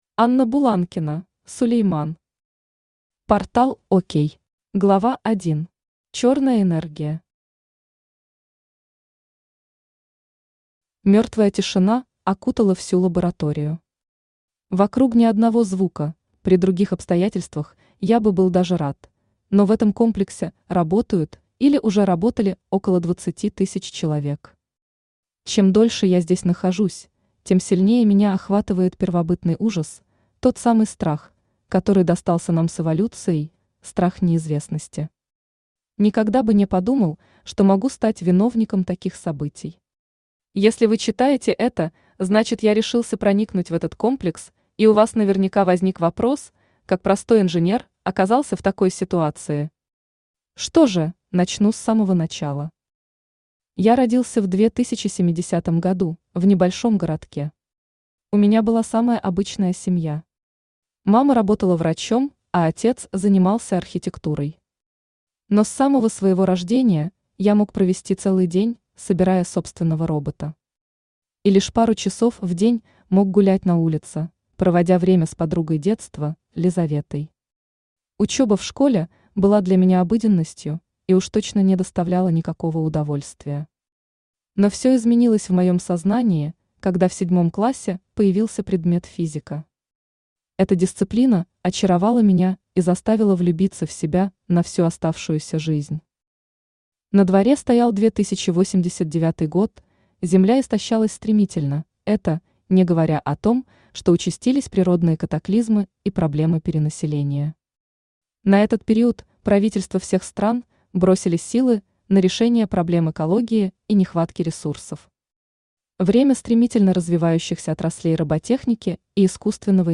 Aудиокнига ПорталОк Автор Анна Сергеевна Буланкина Читает аудиокнигу Авточтец ЛитРес.